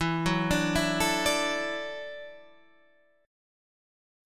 Listen to E7sus2sus4 strummed